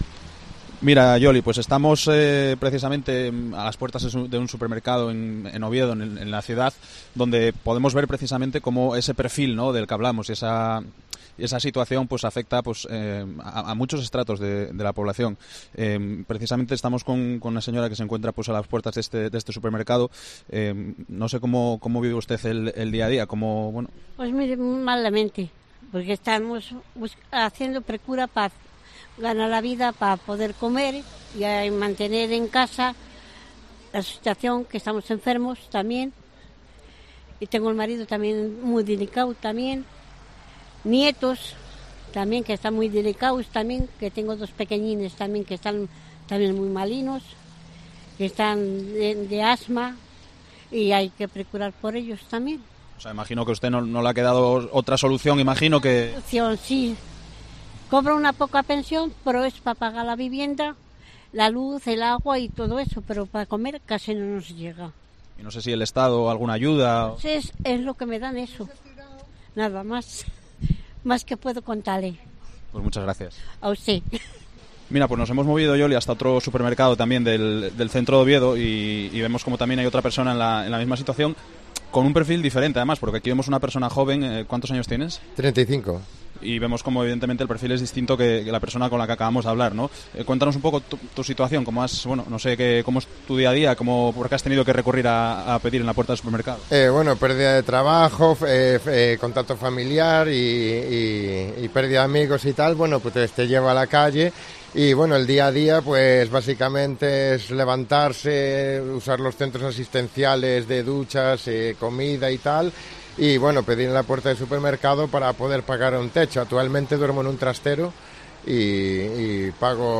En COPE Asturias le ponemos voz a esos datos, con testimonios de personas que necesitan pedir en la calle para poder sobrevivir.